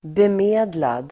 bemedlad adjektiv, well-to-do , of means Uttal: [bem'e:dlad]